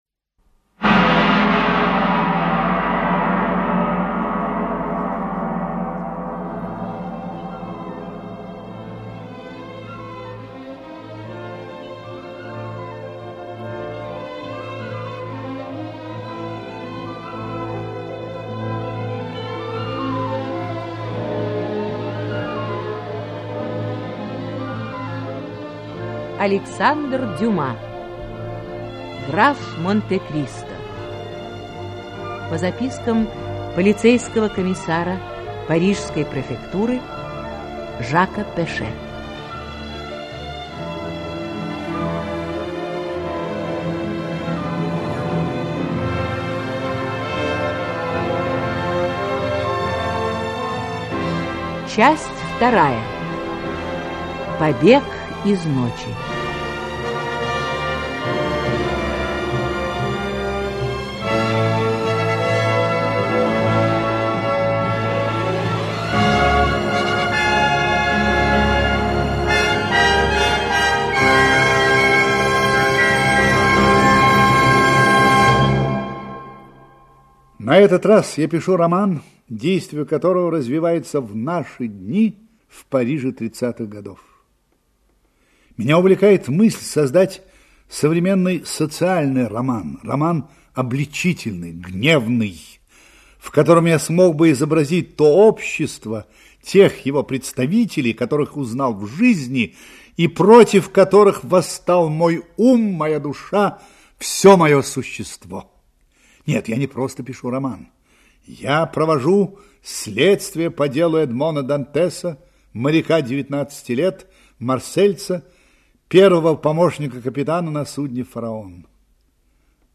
Аудиокнига Граф Монте-Кристо (спектакль) Часть 2-я. Побег из ночи | Библиотека аудиокниг
Aудиокнига Граф Монте-Кристо (спектакль) Часть 2-я. Побег из ночи Автор Александр Дюма Читает аудиокнигу Актерский коллектив.